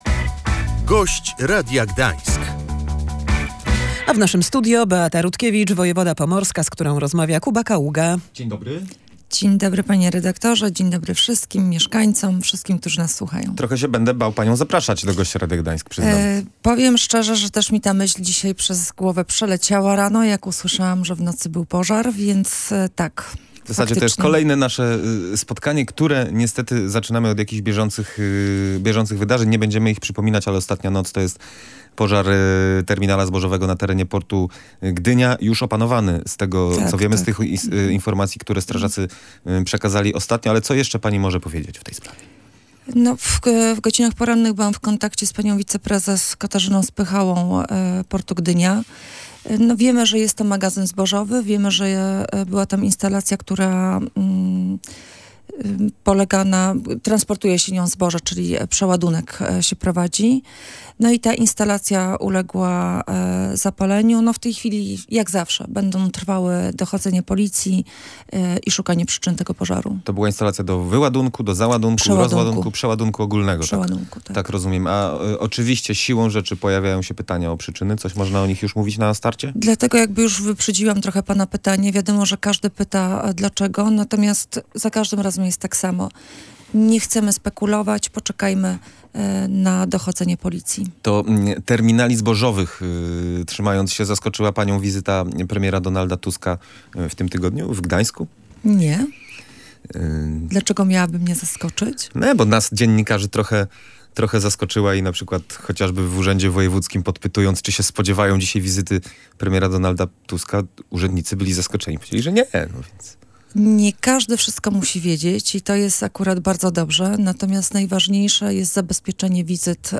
Pożar objął około 300 metrów powierzchni nad magazynem, w którym składowana jest śruta sojowa. Wojewoda pomorska Beata Rutkiewicz mówiła w Radiu Gdańsk, że sytuacja jest opanowana.